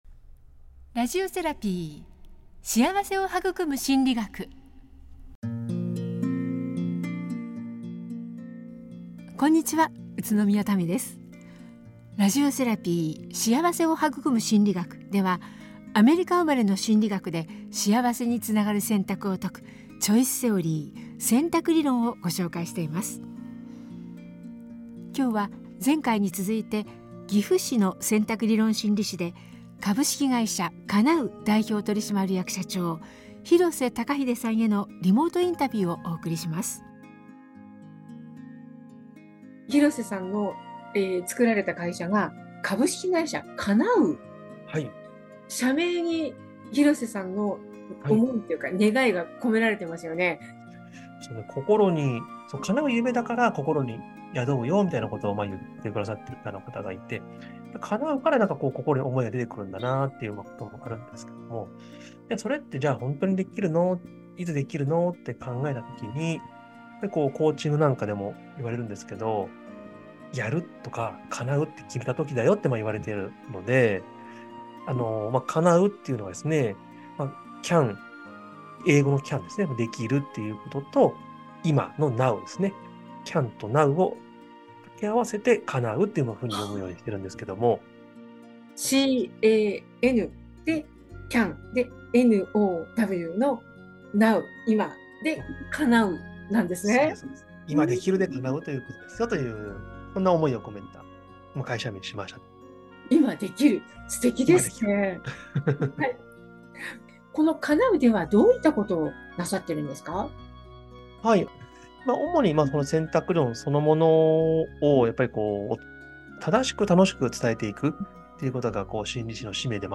ラジオセラピー ～幸せを育む心理学～